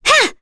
Maria-Vox_Attack3.wav